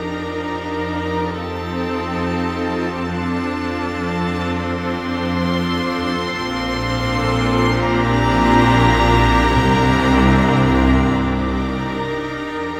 Rock-Pop 17 Strings 04.wav